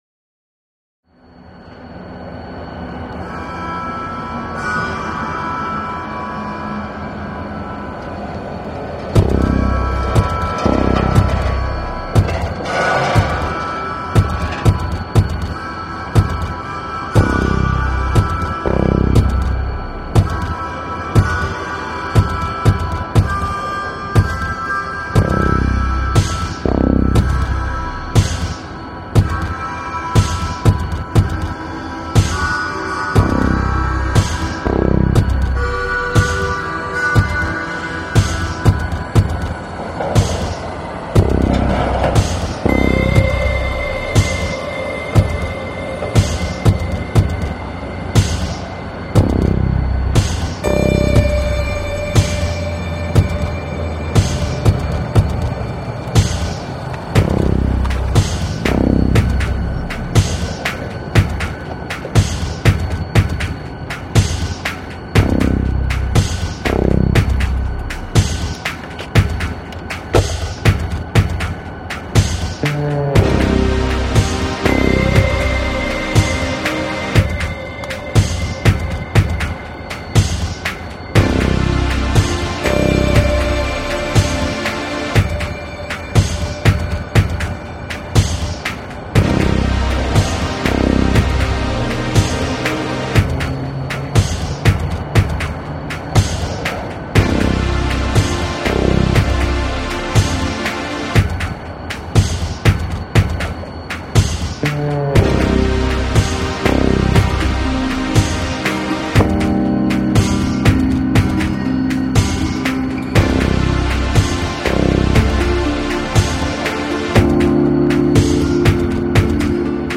The drum beat and snare sound are constructed from the field recording, whilst the other percussion represents the train wheels passing. Rail yards can often be quite deserted and so I chose a lone saxophonist to be playing after the early harmonica. Denver railyard reimagined